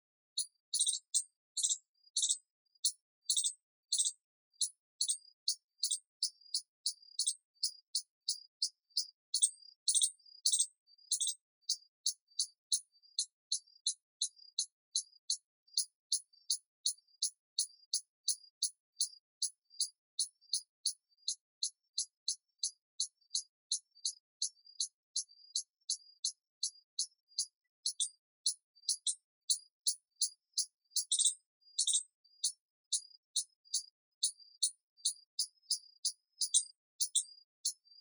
Indian Palm Squirrel Sound